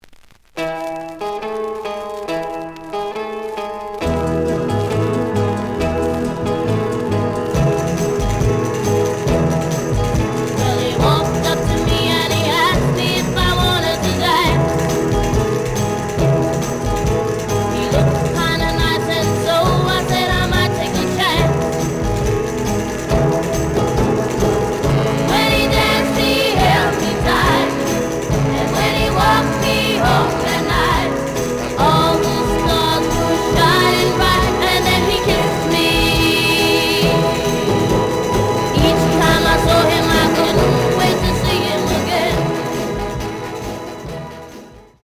The audio sample is recorded from the actual item.
●Genre: Rock / Pop
Some noise on beginnig of A side.